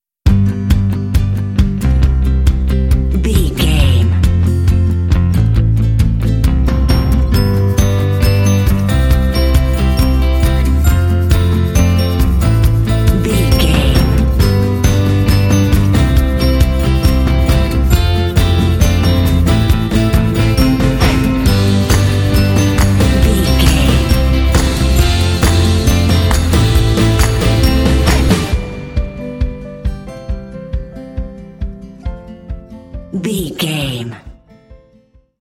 Fun and cheerful indie track with bells and “hey” shots.
Uplifting
Ionian/Major
playful
acoustic guitar
electric guitar
bass guitar
drums
piano
alternative rock
contemporary underscore